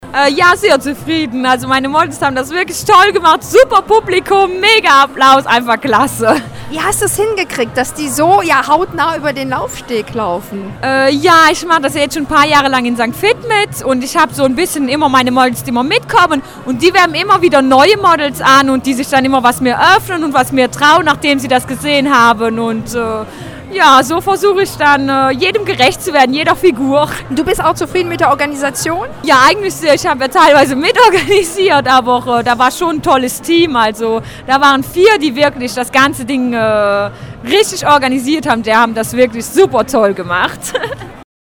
Am Samstag drehte sich im vollbesetzen Mozartsaal in Worriken alles um das Thema „Die aktuellen Modetrends für den Herbst und den Winter“.
Besucher und Veranstalter zeigten sich im Gespräch